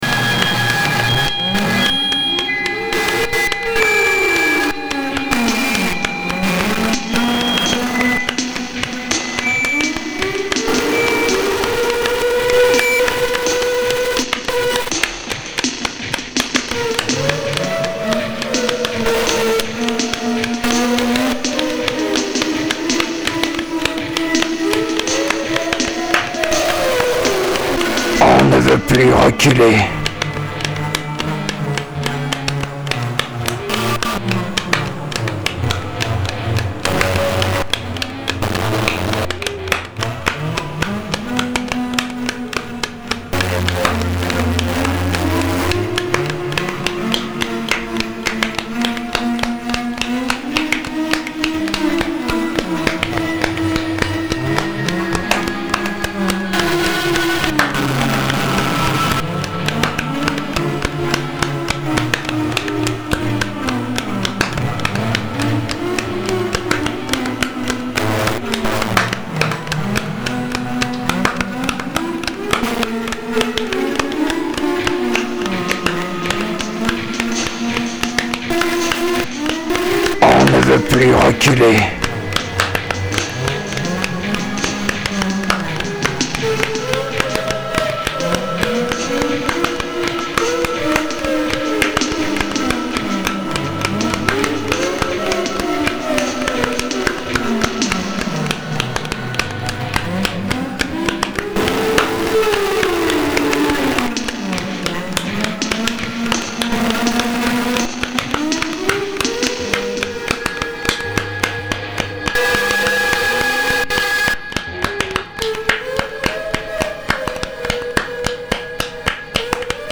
abstract and non-linear electronica